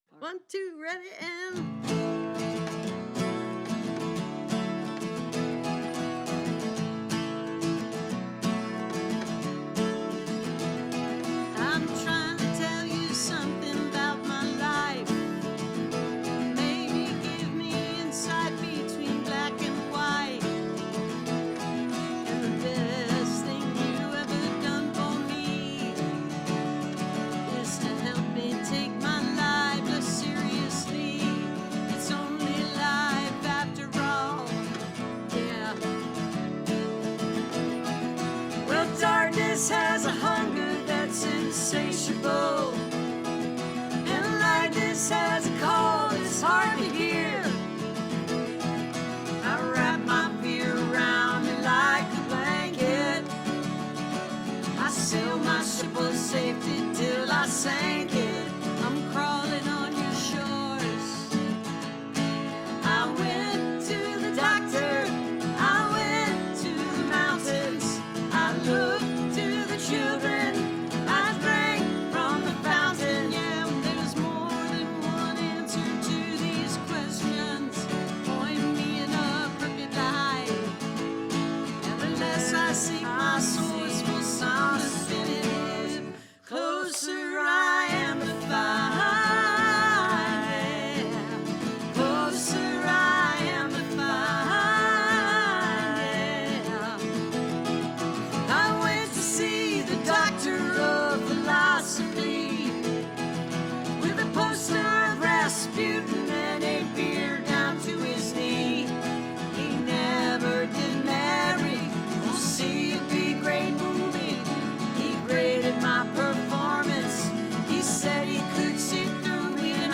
(captured from youtube broadcast)